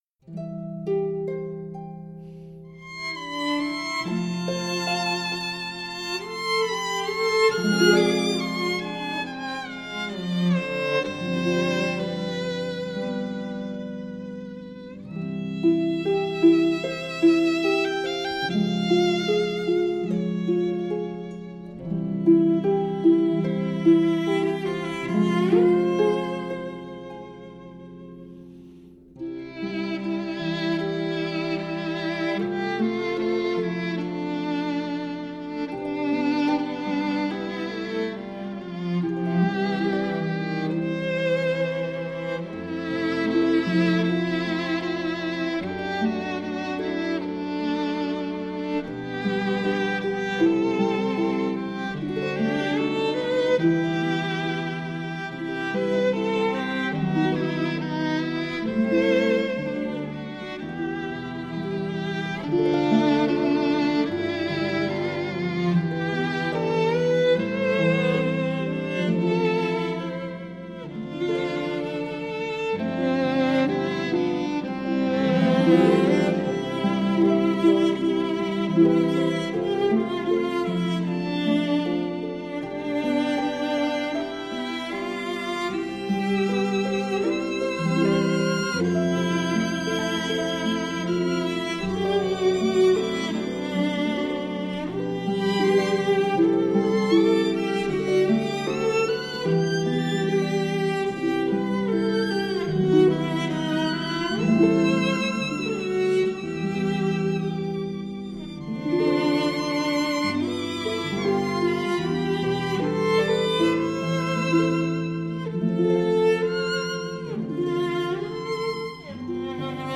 13首揉合异地和家乡泥土芬芳的世界民谣 最具质感的原音重现
【奥地利民谣】